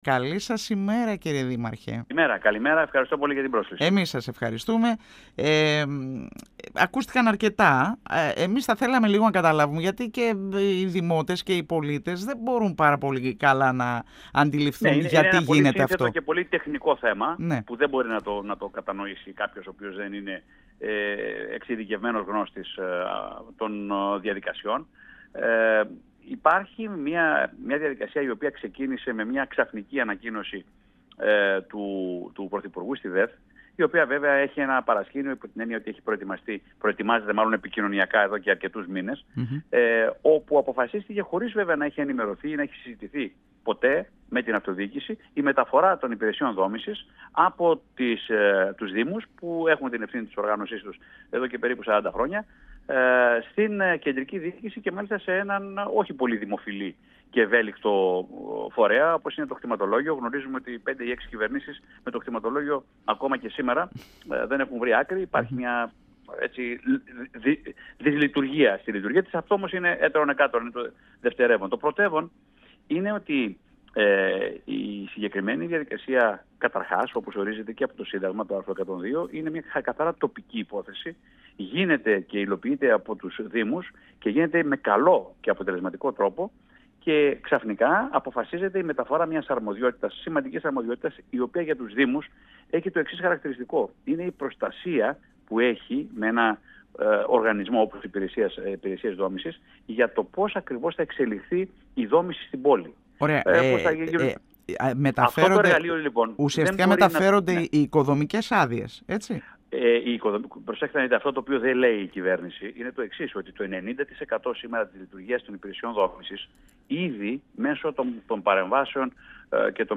Στο ενδεχόμενο προσφυγής στη Δικαιοσύνη, με την οποία απειλούν οι δήμοι της χώρας για τη σχεδιαζόμενη μεταφορά της αρμοδιότητας των Πολεοδομιών στο Κτηματολόγιο από την κυβέρνηση αναφέρθηκε ο Α’ Αντιπρόεδρος της Κεντρικής Ένωσης Δήμων Ελλάδας ( Κ.Ε.Δ.Ε.), δήμαρχος Βάρης – Βούλας – Βουλιαγμένης Γρηγόρης Κωνσταντέλλος, μιλώντας στην εκπομπή «Εδώ και Τώρα» του 102FM της ΕΡΤ3.